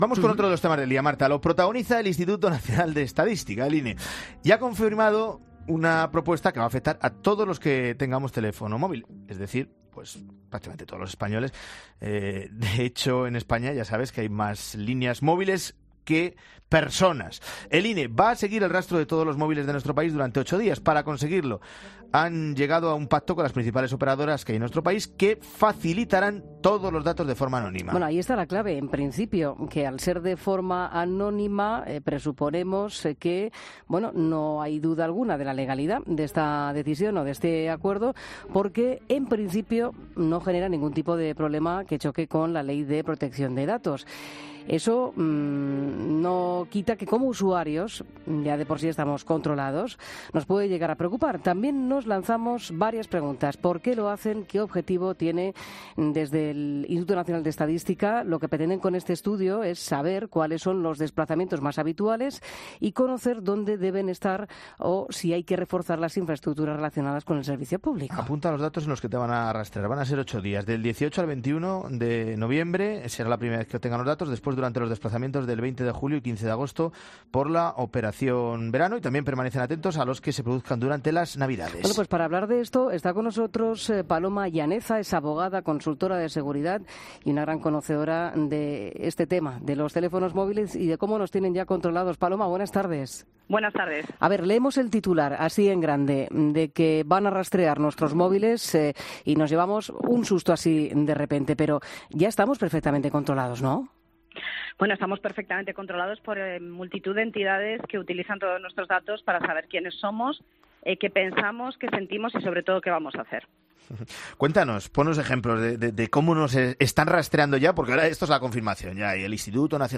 La experta tiene claro que “si esto lo hicieran con cámaras de seguridad no lo consentiríamos”.